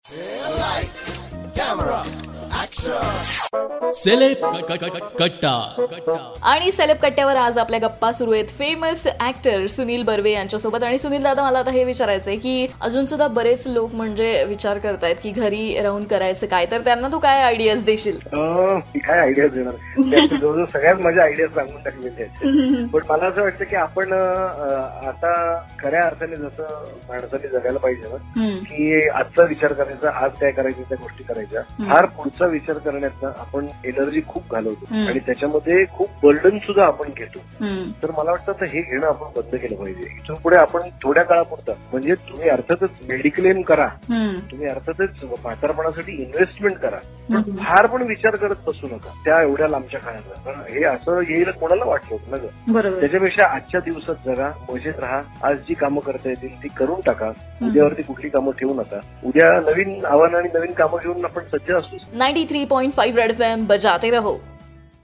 interview of famous actor Sunil Barve..In this interview Sunil Barve Gave some nice ideas for listeners that they should now learn to live in Present ,don't think about future ..Just go with the flow..